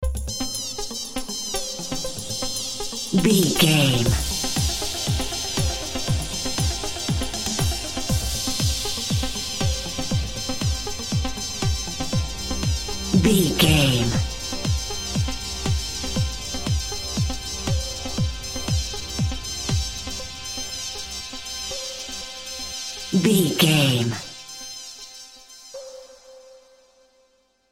Epic / Action
Fast paced
Aeolian/Minor
Fast
dark
futuristic
groovy
aggressive
repetitive
synthesiser
drum machine
house
techno
trance
instrumentals
synth leads
synth bass
upbeat